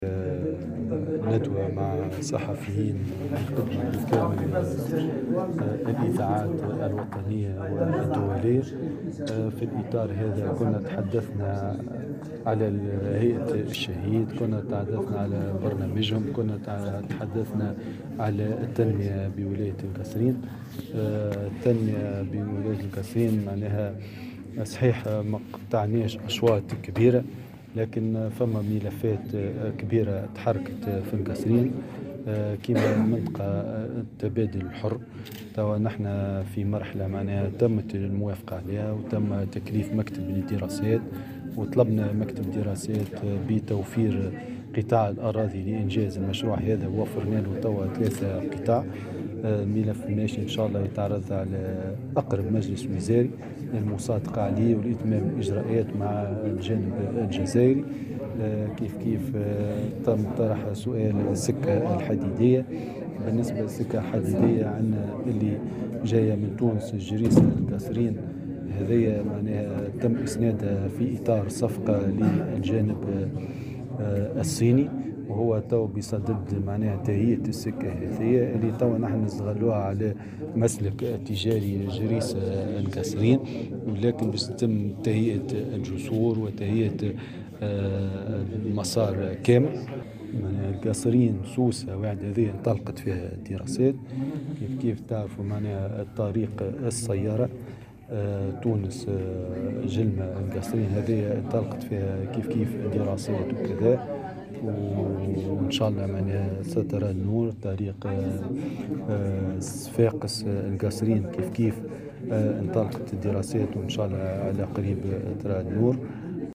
تطرق والي الجهة محمد شمسة صباح اليوم 06 جانفي 2020 الى المشاكل التنموية  بالجهة خلال ندوة صحفية اشرف عليها بمركز الولاية اثر طلب من هيئة مهرجان الشهيد للحديث عن تطور انجاز المشاريع و الاستثمار بالجهة.